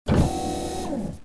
mechanical_door.mp3